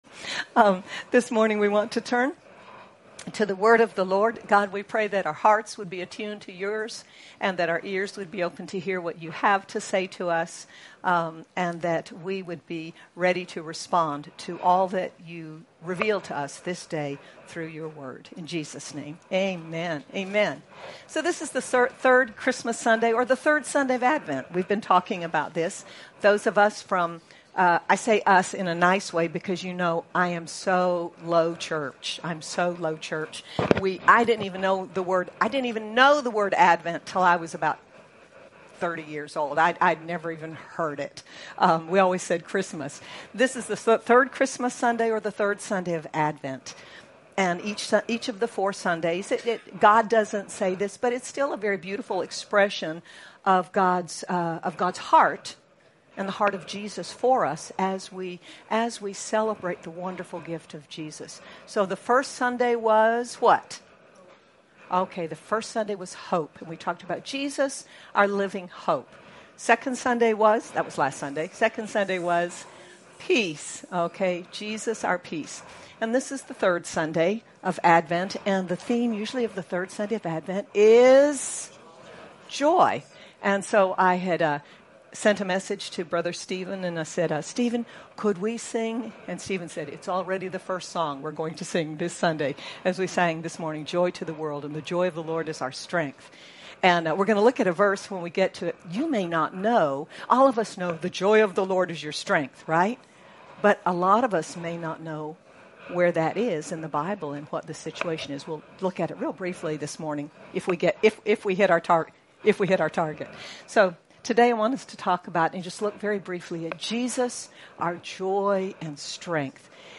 Dec 27, 2025 Jesus, Our Joy and Strength MP3 SUBSCRIBE on iTunes(Podcast) Notes Discussion Sermons in this Series On the third Sunday of Advent, we open our hearts to the joy Jesus offers us through the power of the Holy Spirit. Sermon By